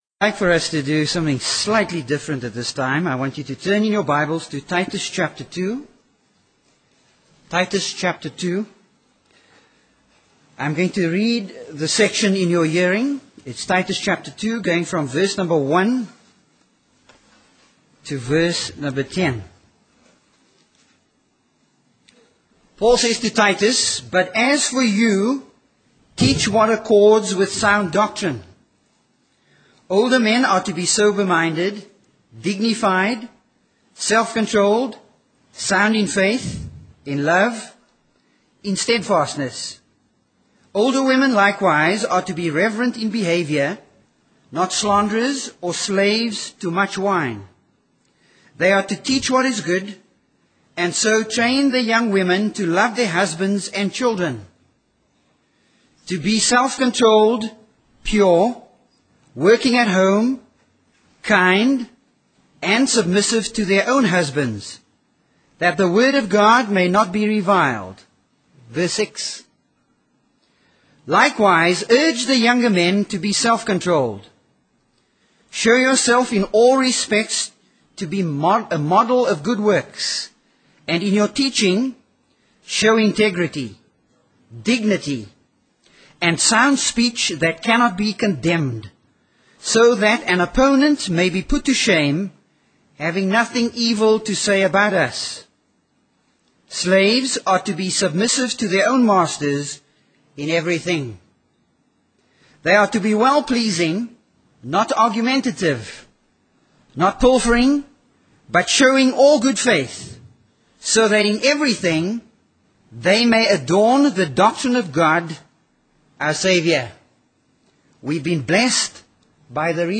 Sermons | Grace Minister's Conference